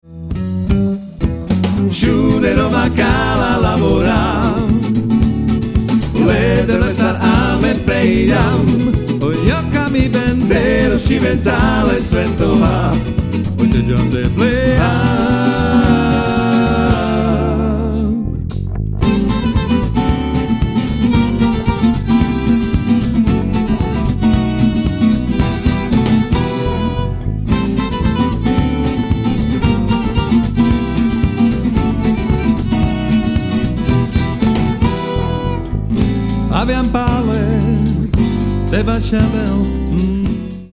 Etnická hudba